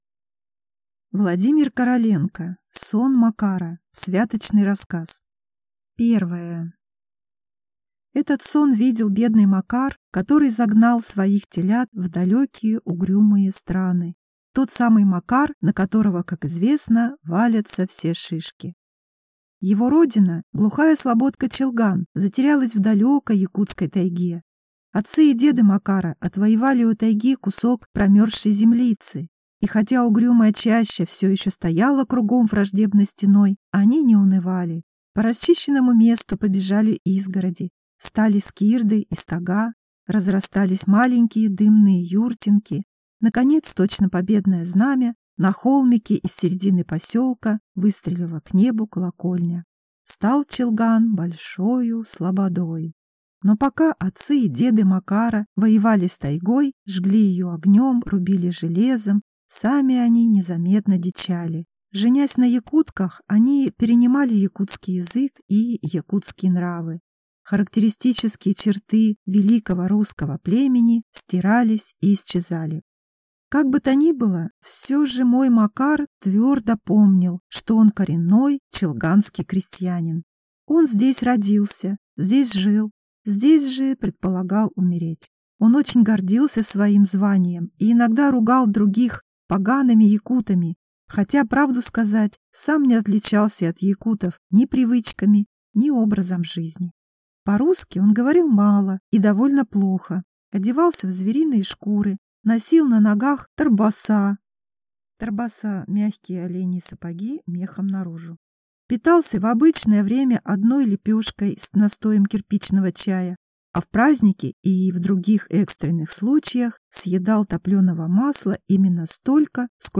Аудиокнига Сон Макара | Библиотека аудиокниг